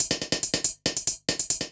Index of /musicradar/ultimate-hihat-samples/140bpm
UHH_ElectroHatD_140-05.wav